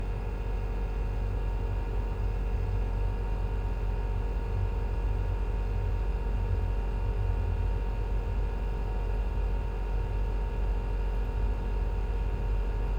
DeltaVR/Vehicles, Electric, Car, Tesla, Model 3, Idle, Engine Compartment 03 SND66189 1.wav at 60b9f21a61e9a486abe491c3d94bef41b06a9b5f